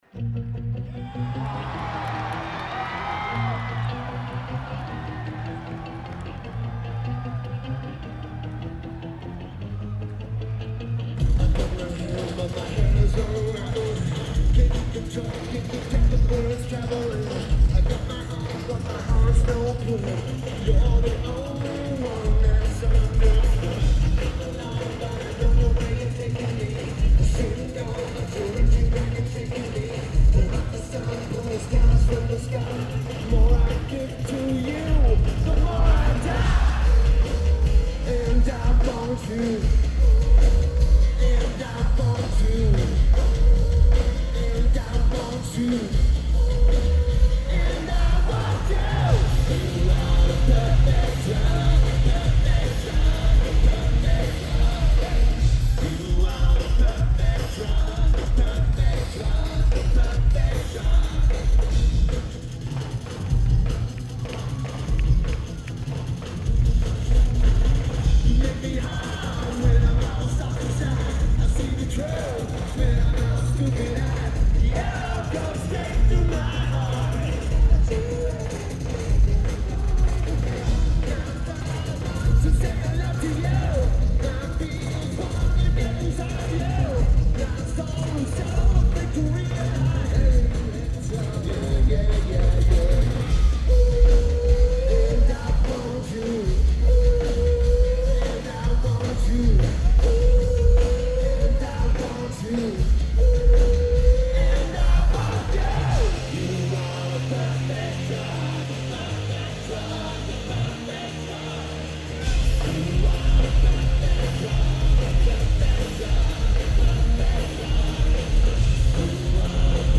Greek Theatre
Sound is amazing on this one!